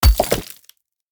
axe-mining-ore-5.ogg